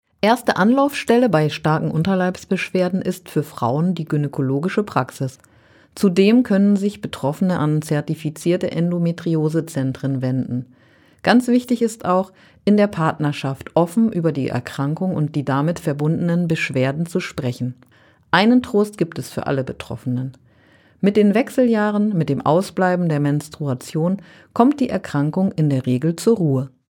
34_oton_endometriose_2_hilfe.mp3